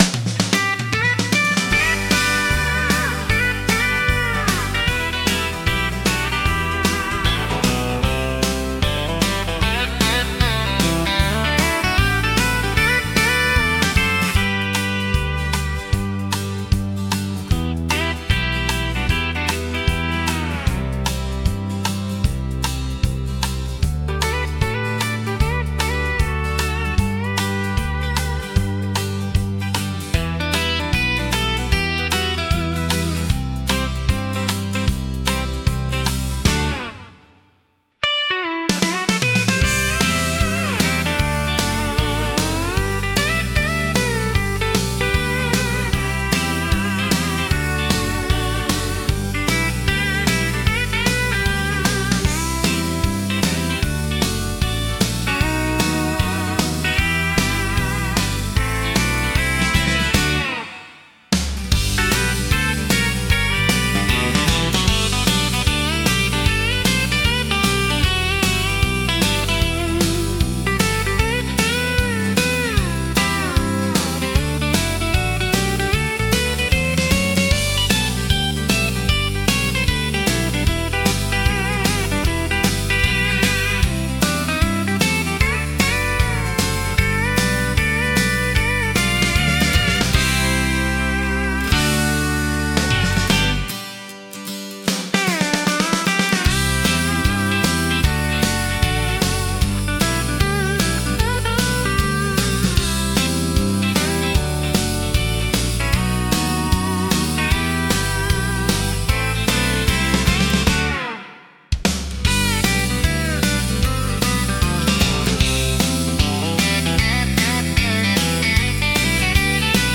聴く人に快適で穏やかな気持ちをもたらし、ナチュラルで親近感のある空気感を演出します。